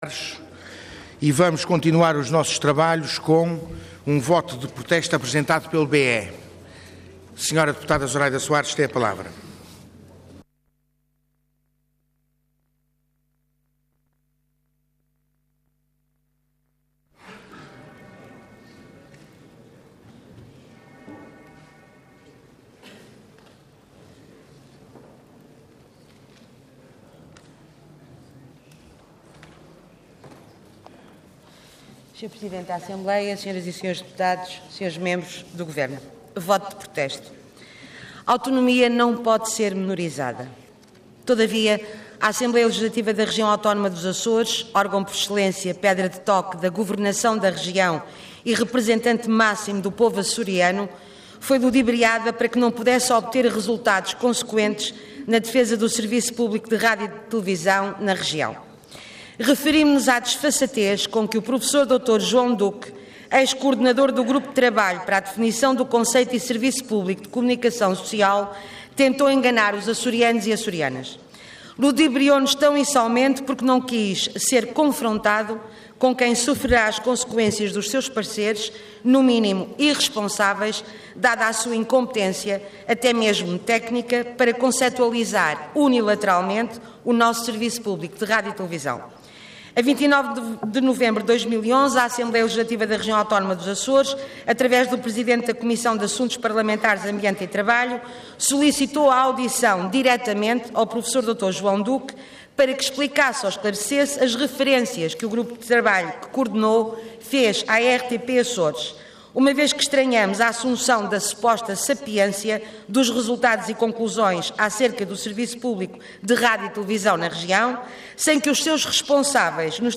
Intervenção
Orador Zuraida Soares Cargo Deputada Entidade BE